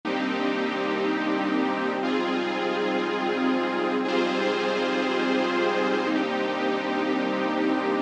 Ahora echemos un vistazo a nuestra pista de pads. Es bastante estática en el sentido de que el ritmo son solo notas completas de acordes sostenidos.